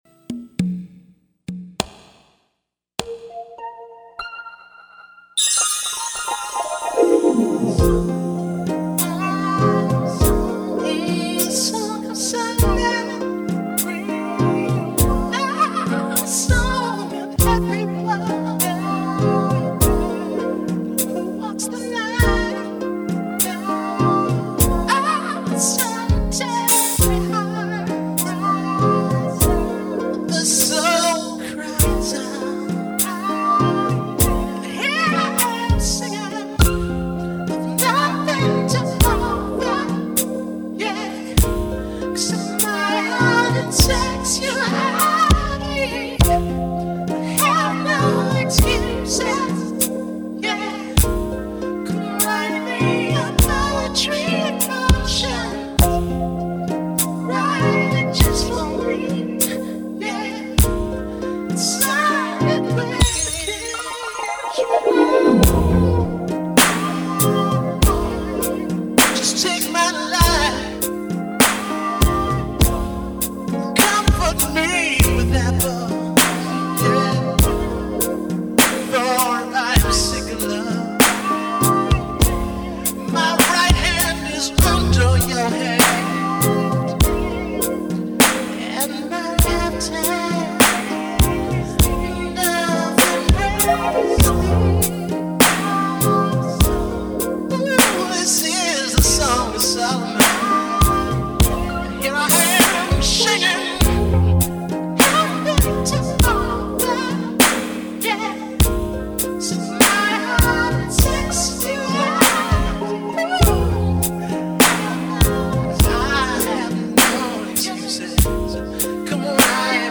All Instruments & Vocals
Turntablism & Scratches
Raps
Vocals